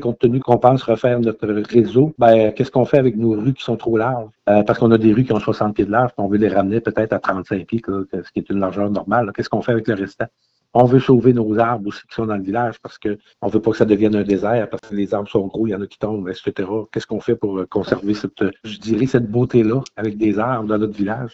Les travaux qui seront réalisés changeront légèrement le visage de la Municipalité, comme l’a expliqué le maire, Guy St-Pierre,